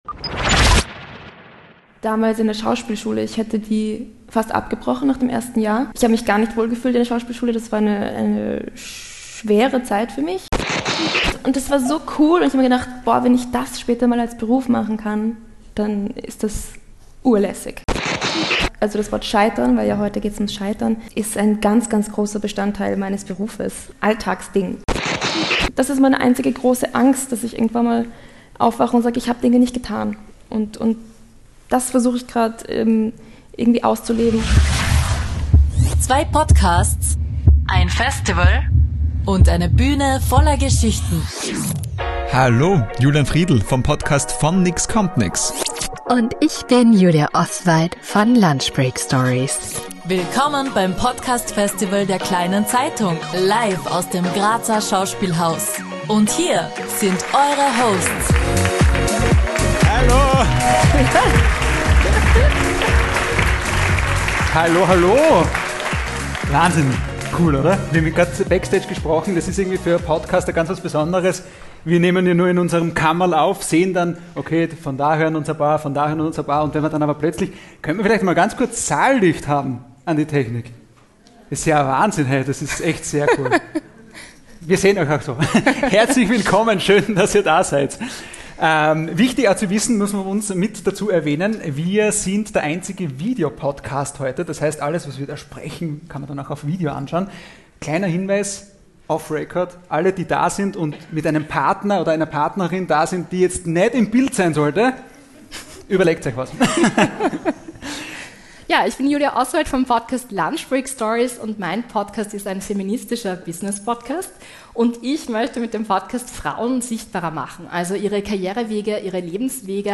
Live vom Kleine Zeitung Podcast Festival 2025 ~ lunch break stories Podcast